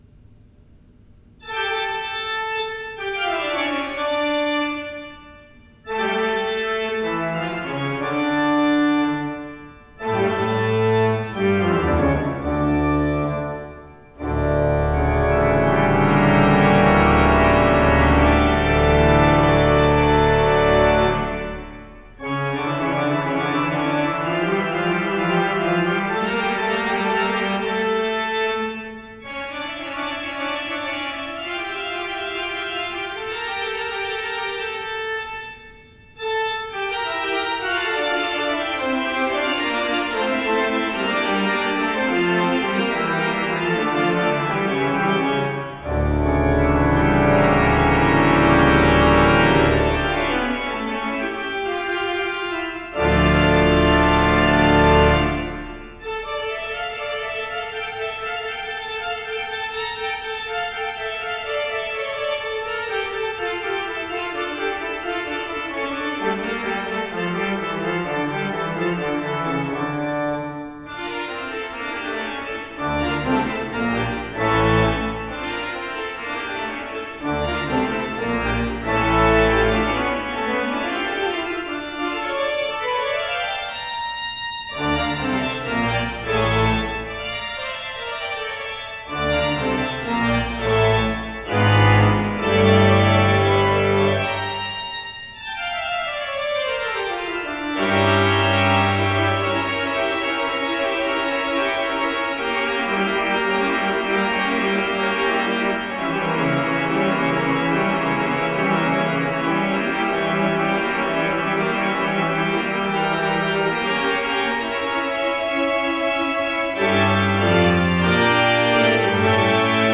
Postludes played at St George's East Ivanhoe 2024
The performances are as recorded on the Thursday evening prior the service in question and are recorded direct to PC using a Yeti Nano USB microphone..